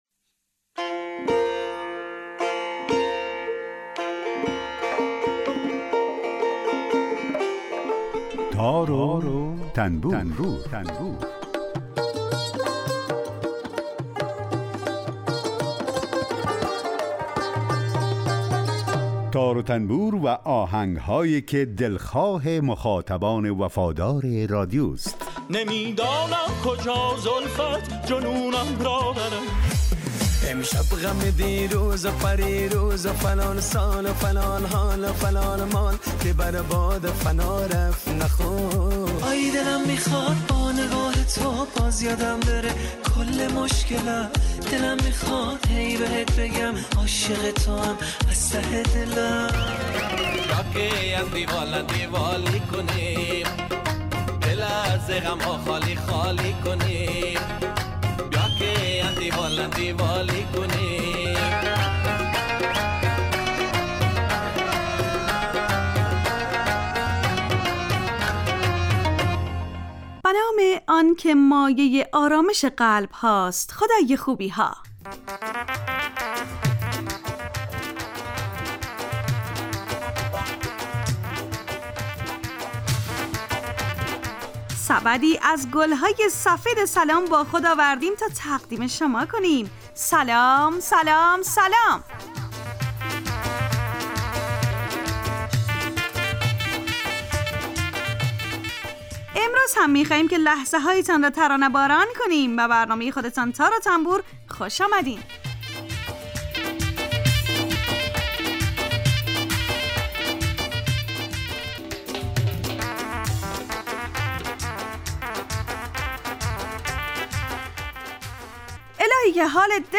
برنامه تار و تنبور هر روز از رادیو دری به مدت 30 دقیقه برنامه ای با آهنگ های درخواستی شنونده ها کار از گروه اجتماعی رادیو دری.